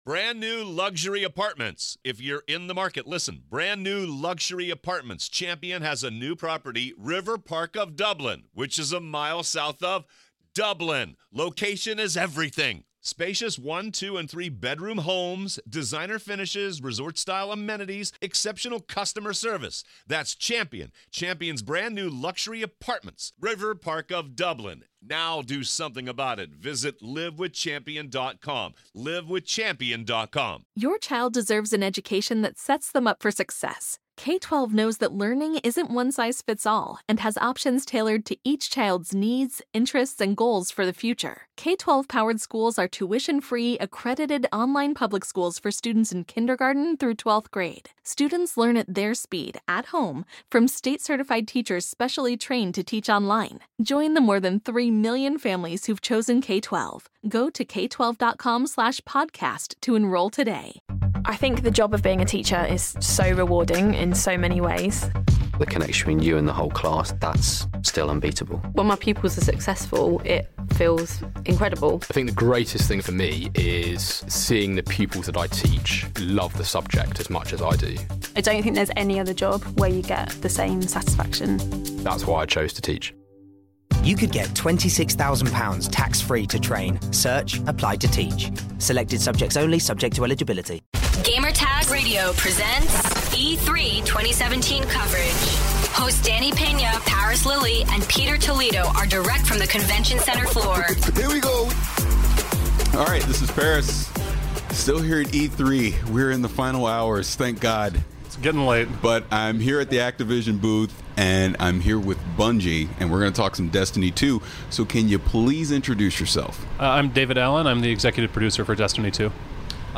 E3 2017: Destiny 2 Interview